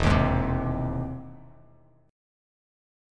E+M7 chord